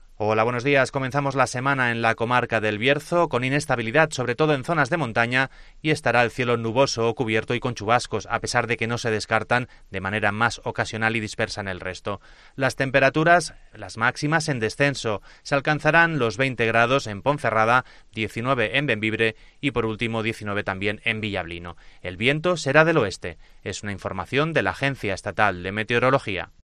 Previsión del tiempo Bierzo